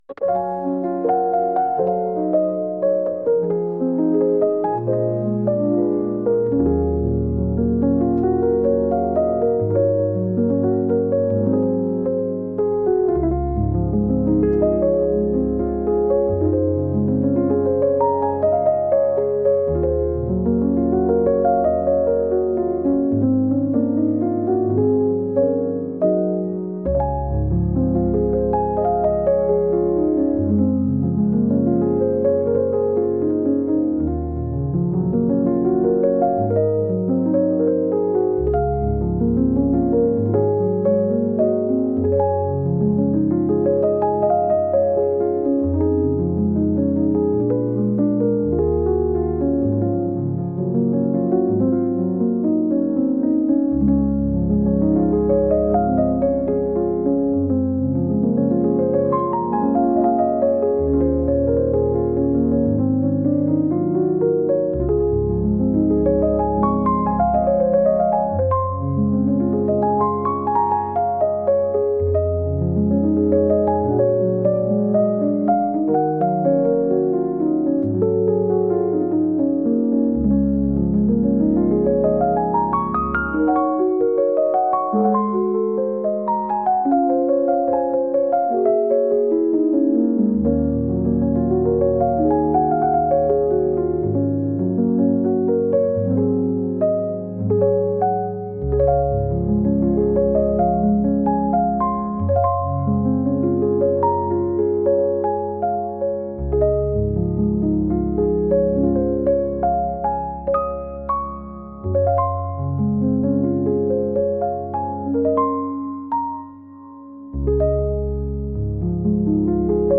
天気のように気分を左右してしまうの君なんだというリコーダー音楽です。